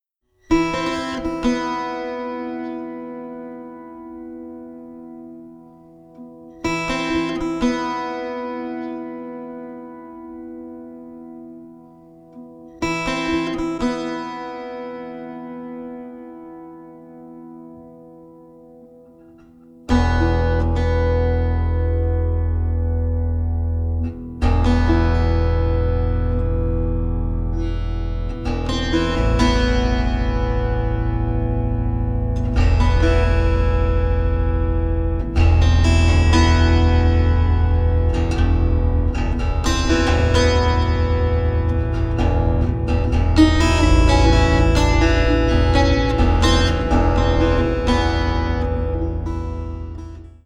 36-string Double Contraguitar, 30-string Contra-Alto guitar